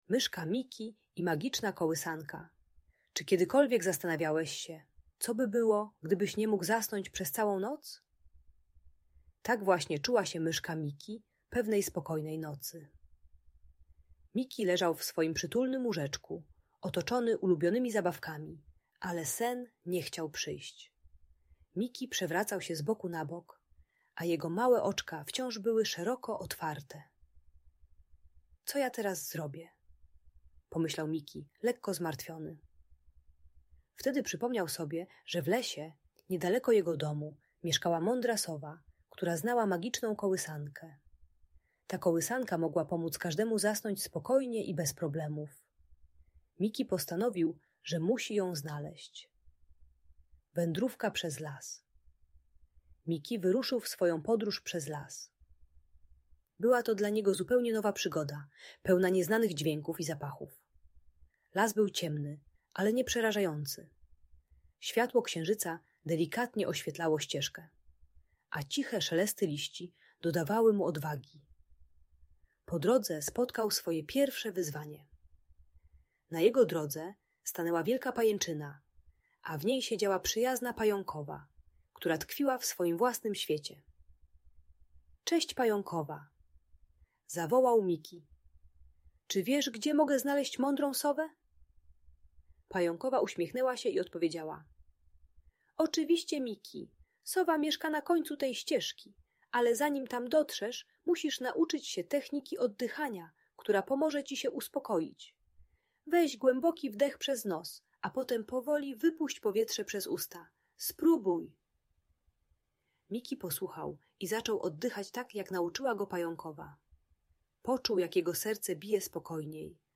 Historia Myszki Miki i Magicznej Kołysanki - Audiobajka